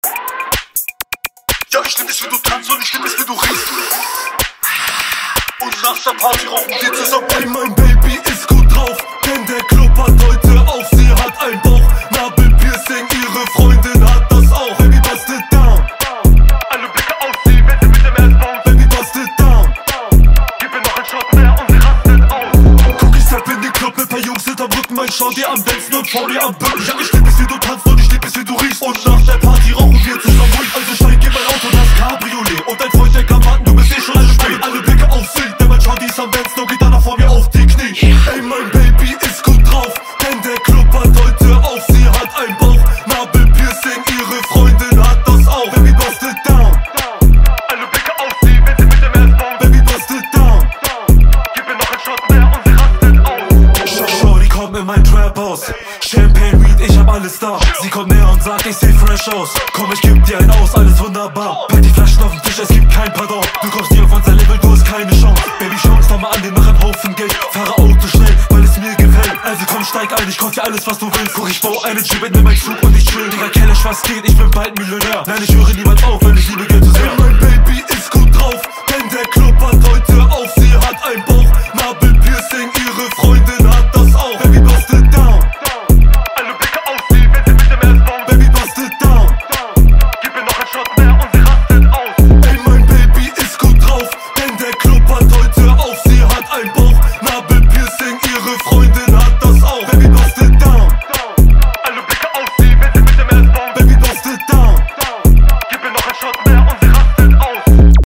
disco